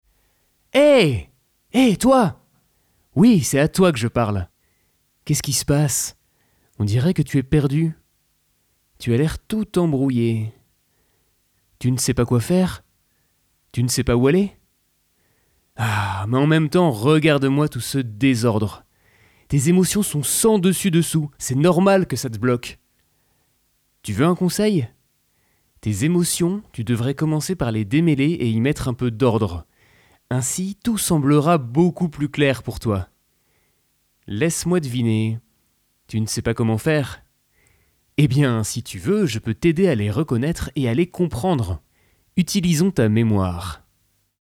Démo voix off
- Ténor